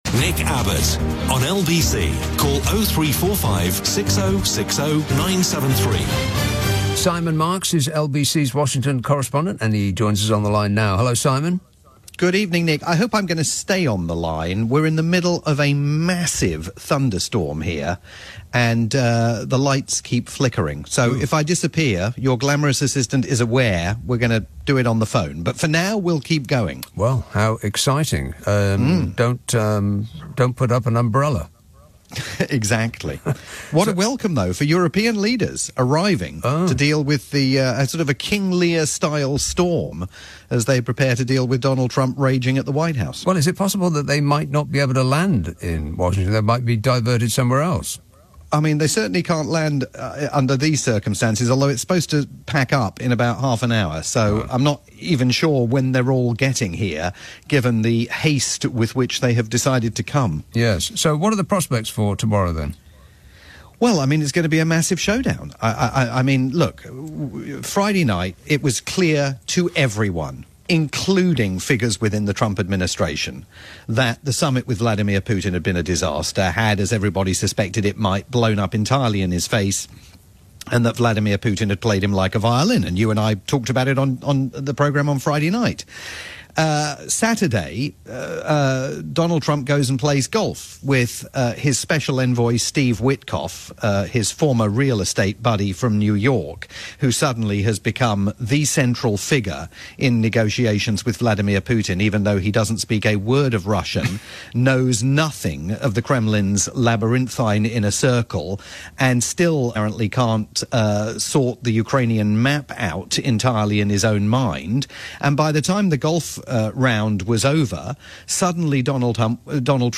live update for Nick Abbot's late night programme on the UK's LBC.